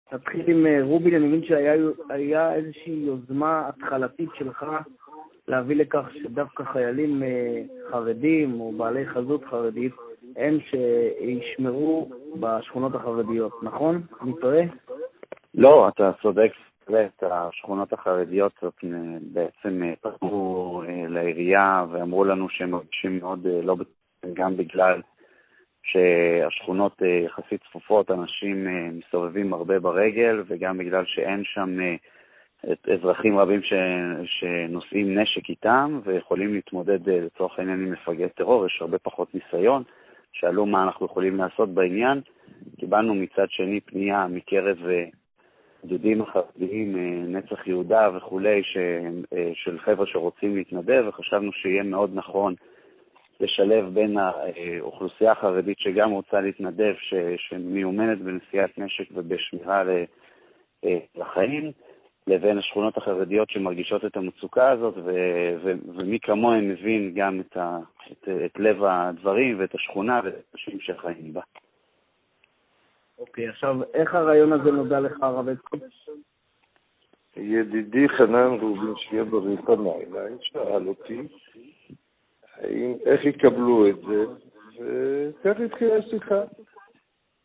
הסקופים בהשתתפות סגר''ע ירושלים הרב חיים
אפשטיין וחנן רובין חבר מעוצת העיר מסיעת התעוררות: